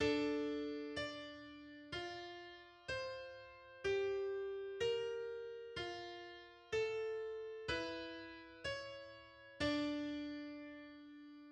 Sincopi
Un esempio di contrappunto di quarta specie (